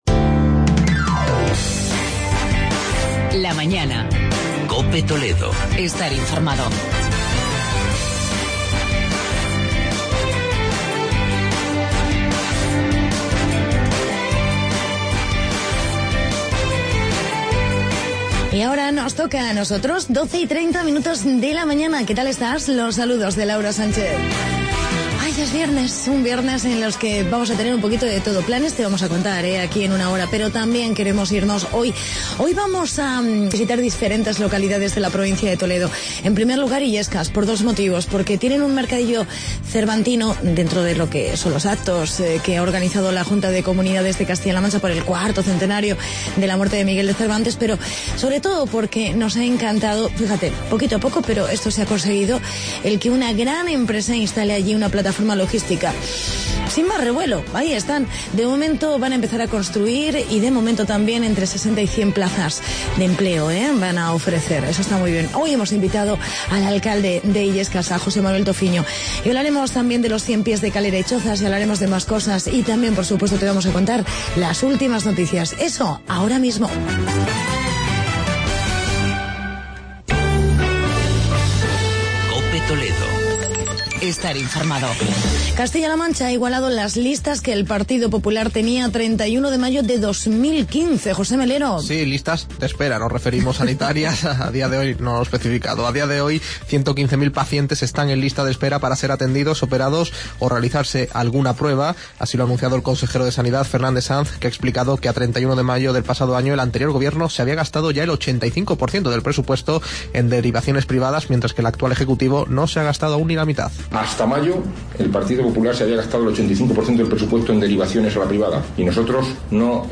Entrevista con el alcalde de Illescas, José Manuel Tofiño